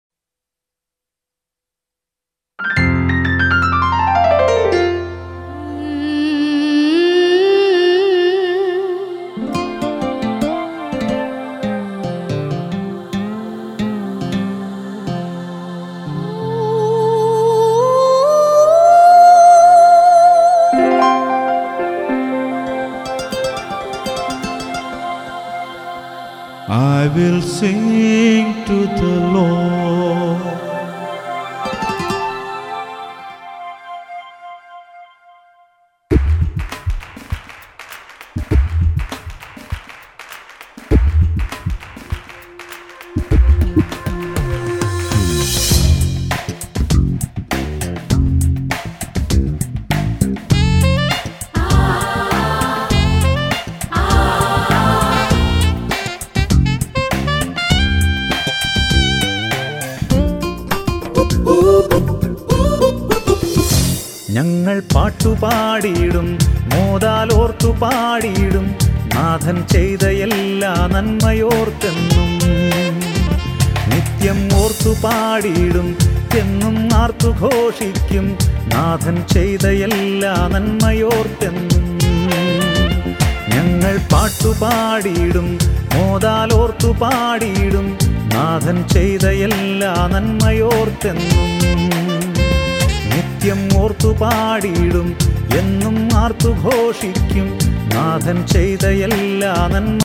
Malayalam Christian Devotional Songs